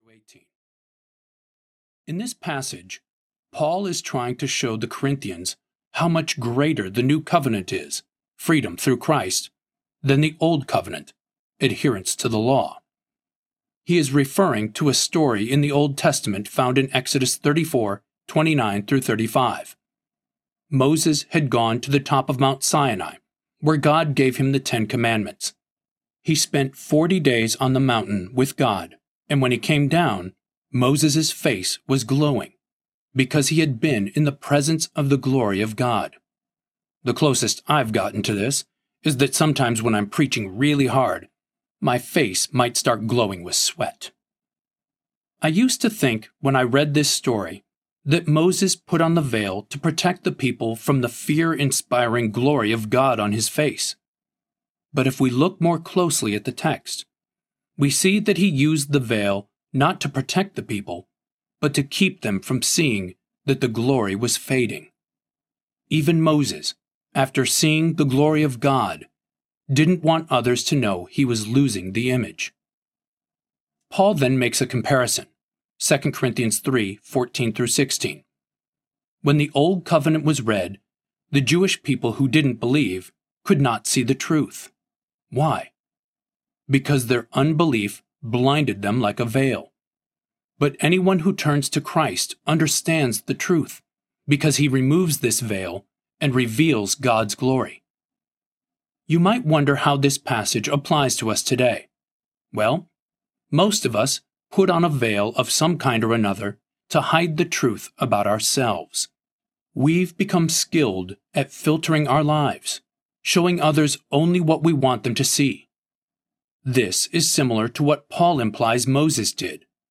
Liking Jesus Audiobook
Narrator
5.33 Hrs. – Unabridged